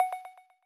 scoge-menu1-move Audio Extracted.wav